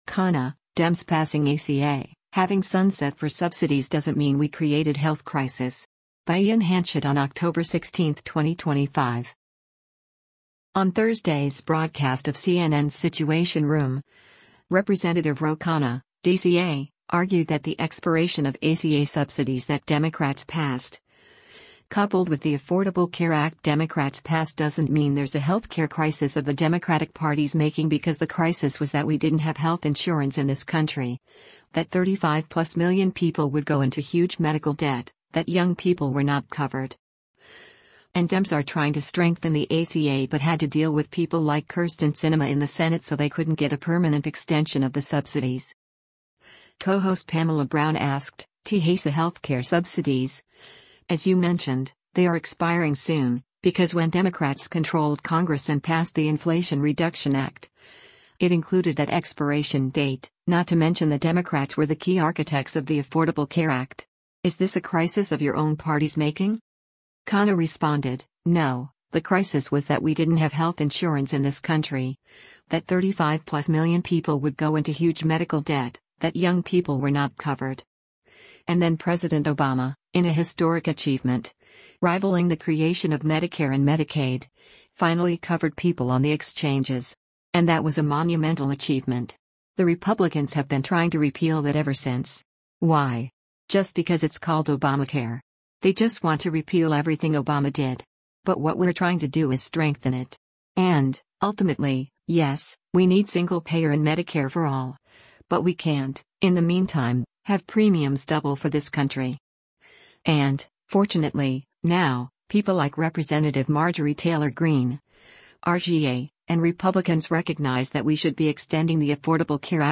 On Thursday’s broadcast of CNN’s “Situation Room,” Rep. Ro Khanna (D-CA) argued that the expiration of ACA subsidies that Democrats passed, coupled with the Affordable Care Act Democrats passed doesn’t mean there’s a healthcare crisis of the Democratic Party’s making because “the crisis was that we didn’t have health insurance in this country, that 35-plus million people would go into huge medical debt, that young people were not covered.”